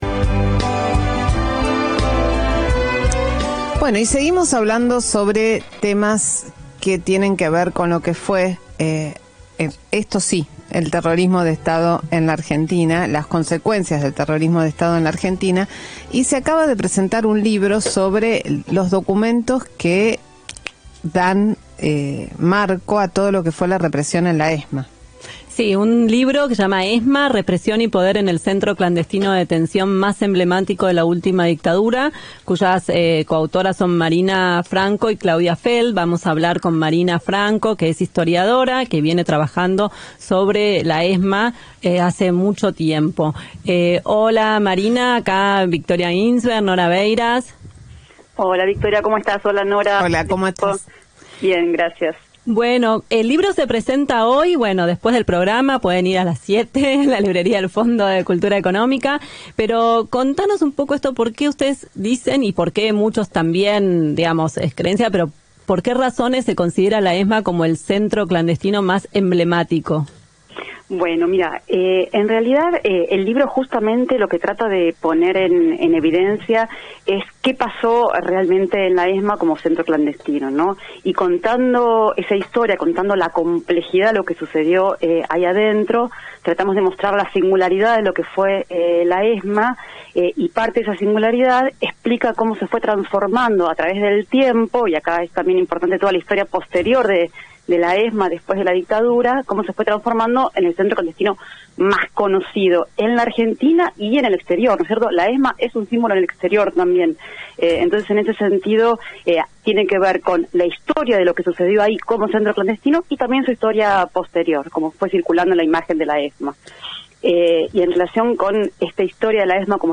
En diálogo Aquí, Allá y en Todas Partes por AM750